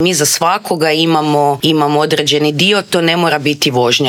U intervjuu Media servisa o tome su pričale naše gošće